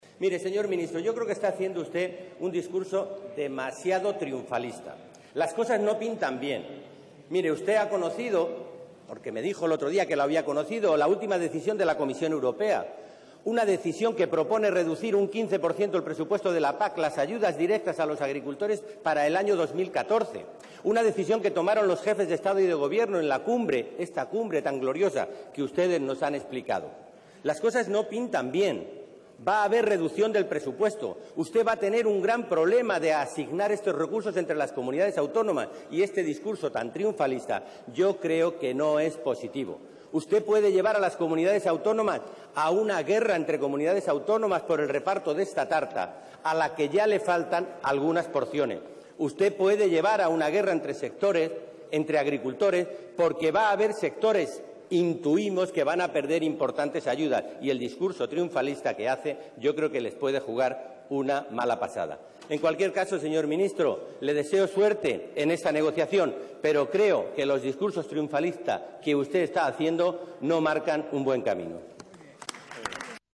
Alonso se ha pronunciado así durante la sesión de control al Gobierno en el Congreso de los Diputados, donde ha preguntado a Arias Cañete si el Gobierno puede garantizar que los agricultores y ganaderos españoles no perderán las ayudas de la PAC en el próximo período 2014-2020, algo que el ministro no ha aclarado.
Cortes de audio de la rueda de prensa
Audio_Alonso-PAC_sesion_control_gobierno.mp3